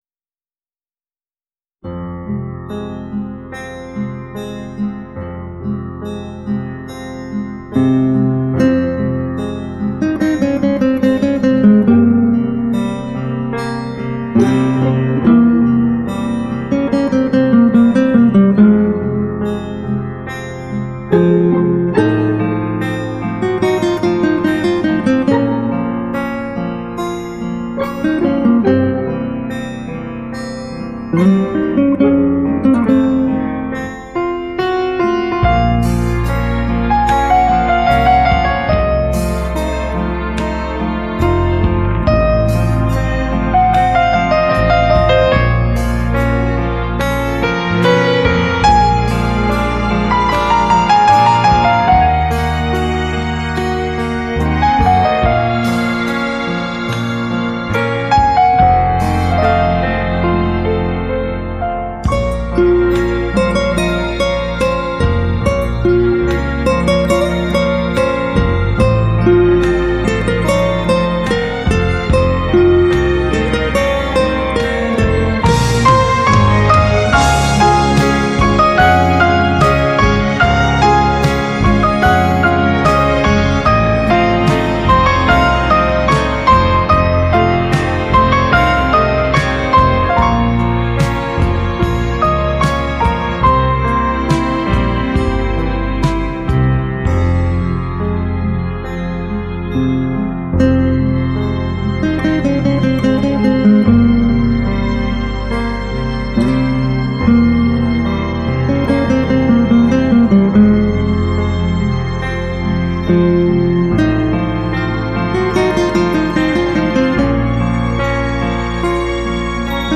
吉他史上一次具代表性的真实录音，采用七支专业顶级麦克风录制
再现高水平的临场感 演奏生猛活泼 音乐温暖 动态十足 旋律优美动听 全碟在加拿大录制